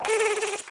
buzz.mp3